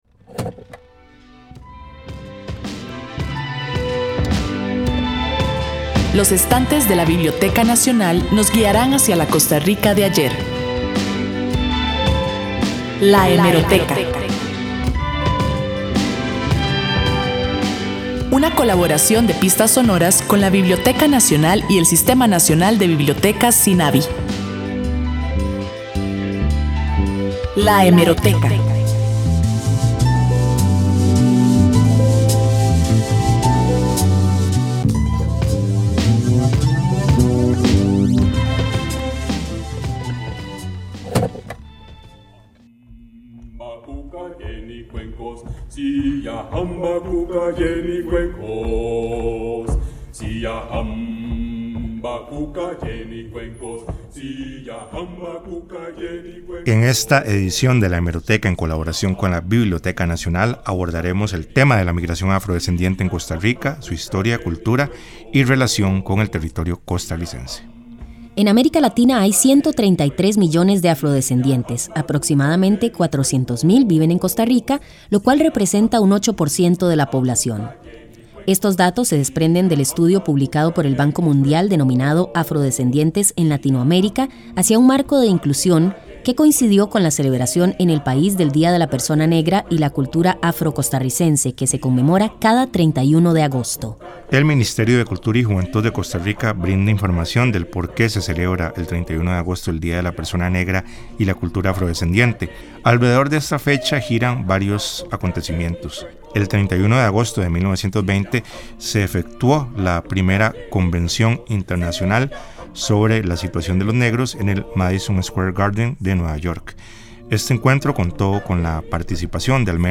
Sección de la Biblioteca Nacional en el Programa Pistas Sonoras de Radio Universidad, transmitido el 21 de mayo del 2022.